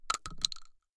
Bullet Shell Sounds
shotgun_generic_2.ogg